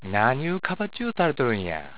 ためになる広島の方言辞典 か．